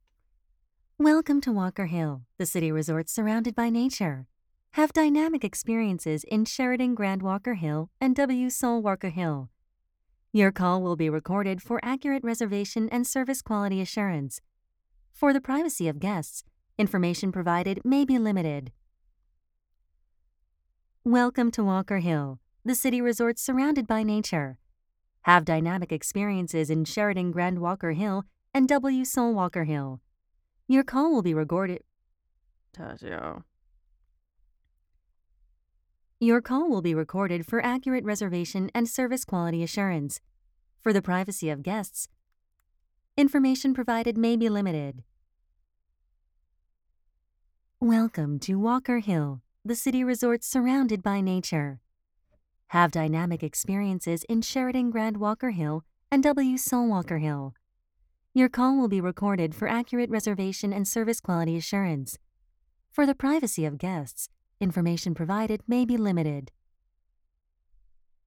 Female
My vocal range extends from a 20's bright and bubbly to a 40's warmly confident.
Phone Greetings / On Hold